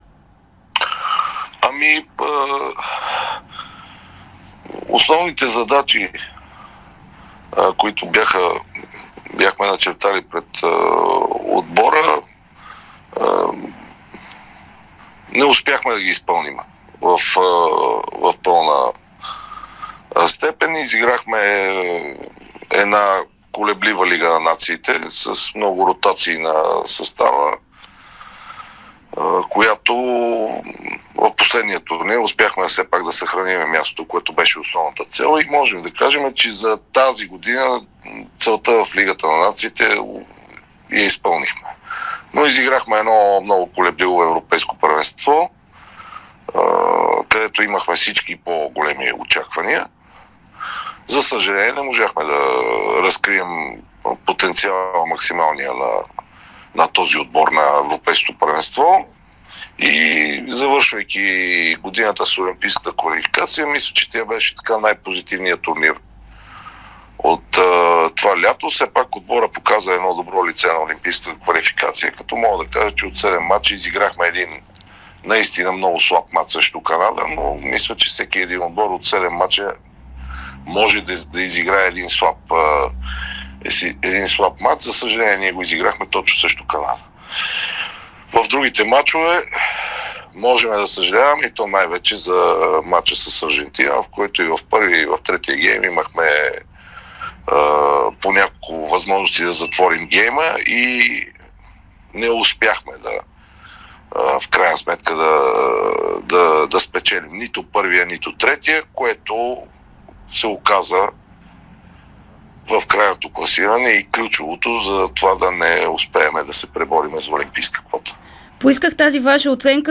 Вече бившият селекционер на българския национален отбор по волейбол Пламен Константинов говори ексклузивно от Русия пред Дарик радио и dsport след края на мачовете на мъжкия ни национален отбор през лятото, в което страната ни игра мачове от Лигата на нациите, Европейското първенство и Олимпийската квалификация за място на Игрите в Париж през 2024.
Той даде ексклузивно това интервю, докато националите кацнаха на българска земя.